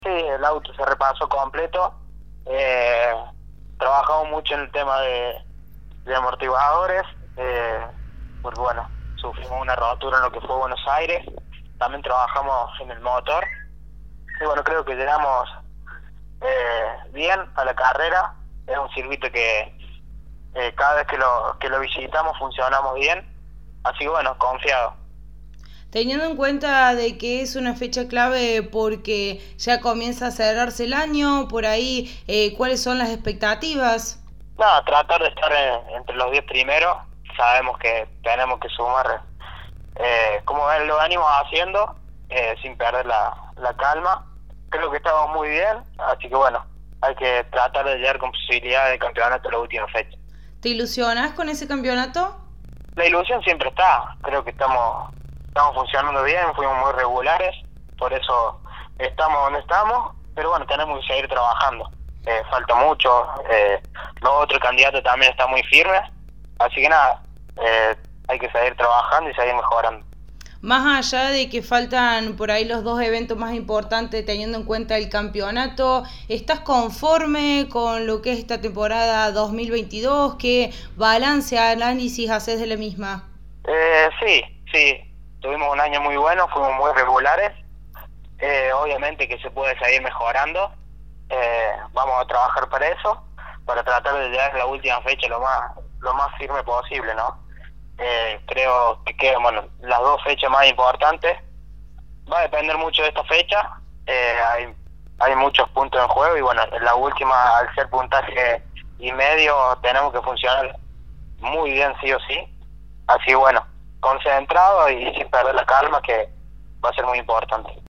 pasó por los micrófonos de Poleman Radio y comentó sobre la puesta a punto. También manifestó las expectativas para el evento y realizó un balance de lo que viene siendo la temporada 2022